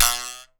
02 BUZZ STIC.wav